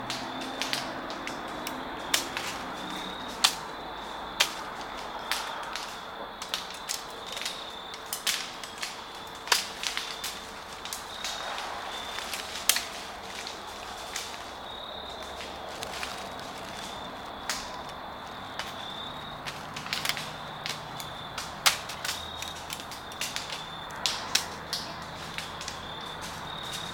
Wood crackle
The sun was barely down. From the edge of a suburban woodlot, ear-sparkles came from the treetops, like the fizz and pop of a small pine-wood fire or the crackle of water droplets in hot bacon grease.
An intermittent rain of velvety seed pods greeted us, the popped remnants of wisteria fruits. In the dry air they were all dehiscing at once, flicking seeds away from the mother plant in tiny explosive releases.